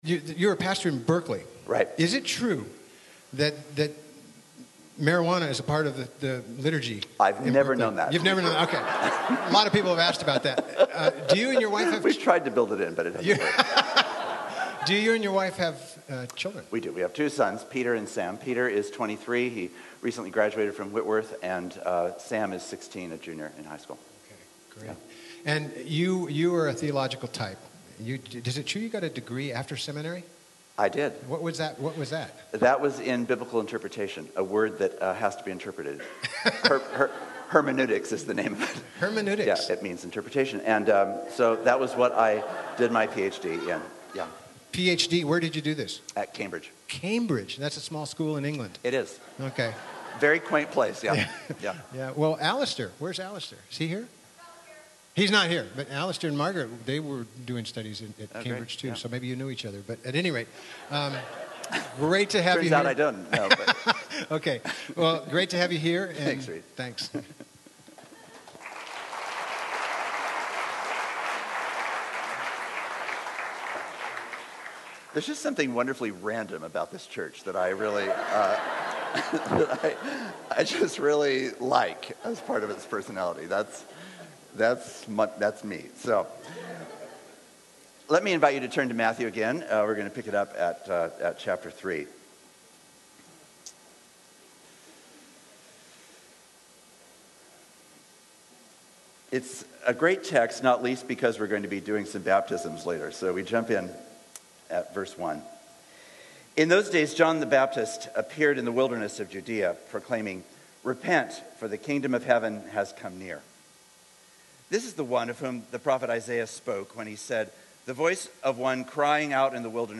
Retreat 2011 Sat. AM
Speaker: Guest Preacher | Series: Church Retreat…